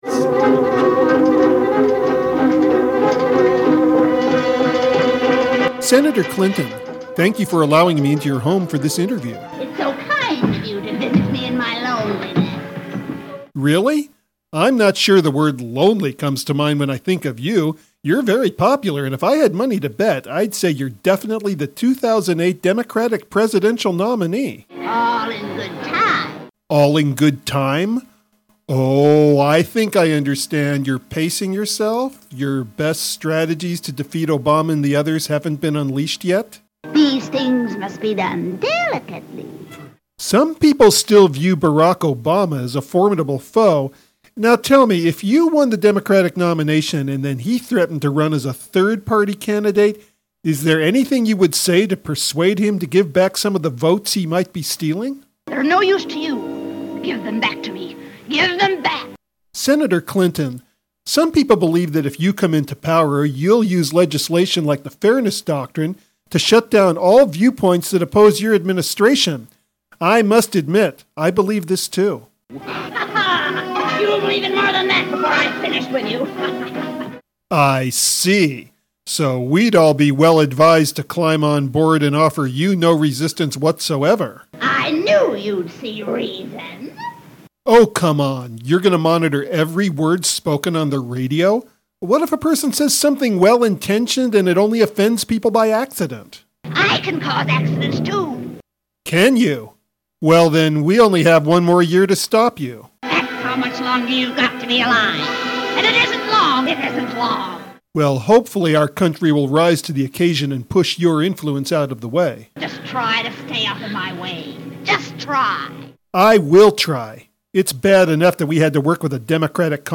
Interview With Hillary Clinton